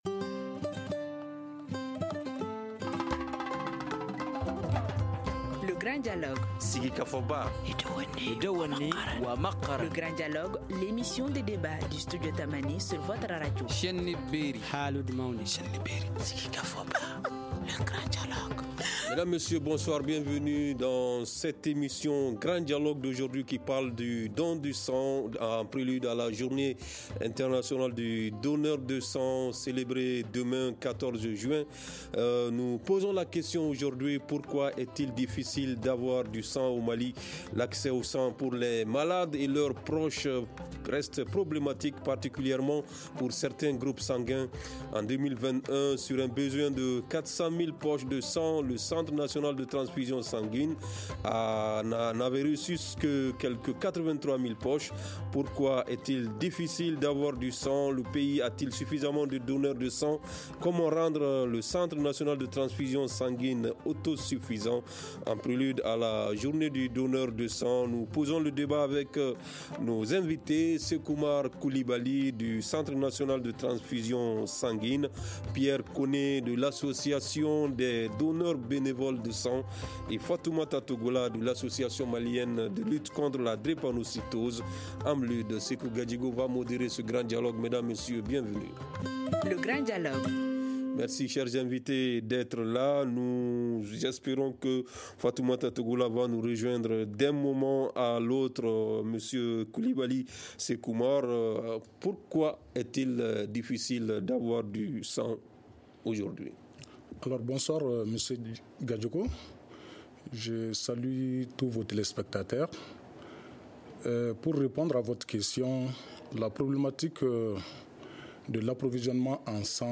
Le pays a-t-il suffisamment de donneurs de sang ? Comment rendre le centre national de transfusion sanguine autosuffisant ? En prélude à la journée du donneur de sang prévue demain 14 juin, nous en parlons avec nos invités